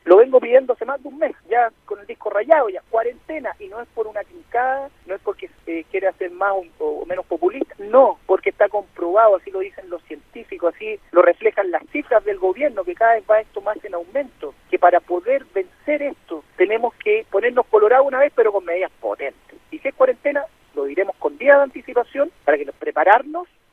El alcalde de Concepción, Álvaro Ortiz, insistió que frente a los números y la estadística, es necesario decretar cuarentena, al margen de otras medidas que también se pueden evaluar desde el Gobierno central.
cua-salud-alcalde-de-conce.mp3